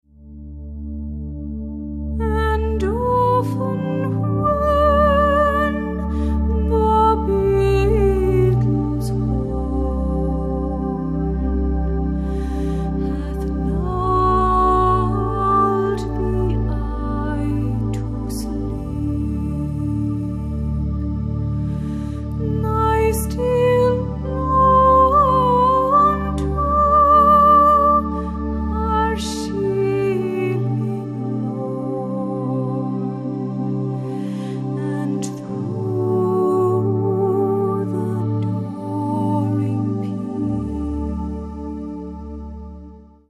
classic Irish songs and melodies